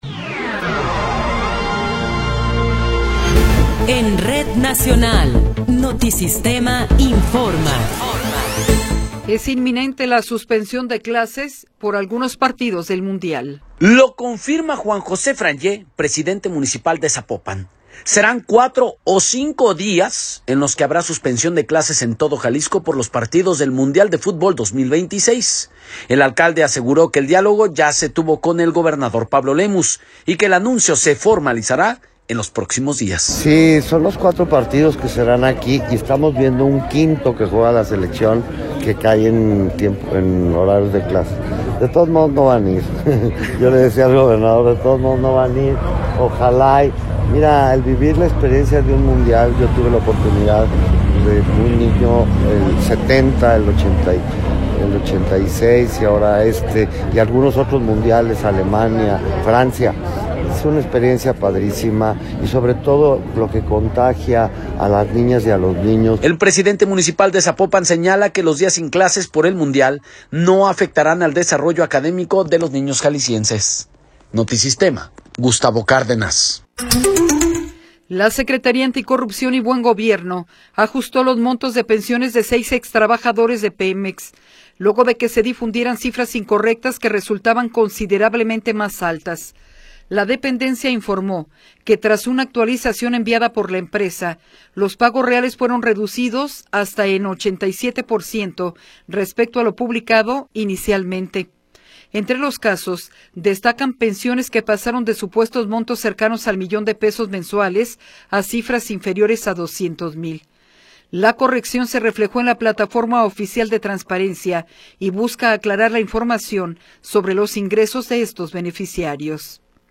Noticiero 18 hrs. – 20 de Marzo de 2026
Resumen informativo Notisistema, la mejor y más completa información cada hora en la hora.